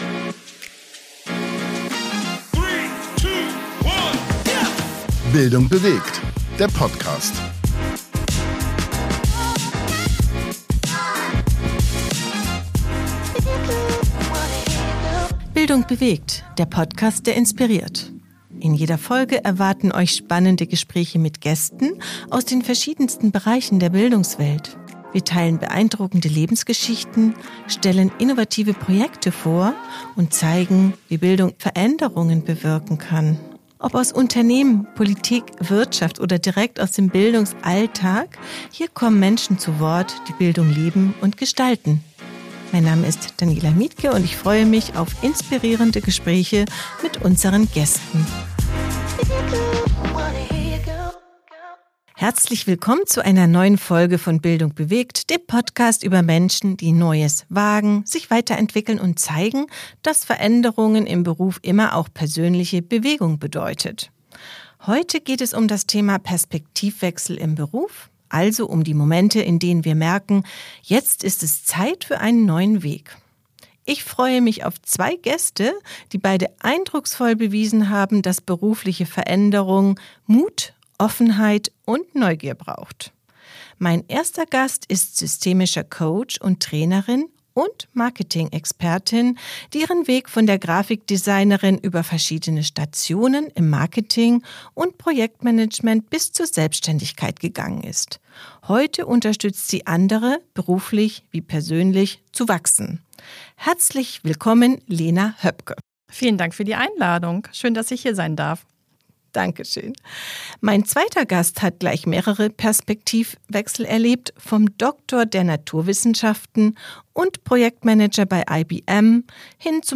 Zwei Gäste erzählen, wie sie berufliche Veränderung erlebt und gestaltet haben – mit Mut, Offenheit und Neugier.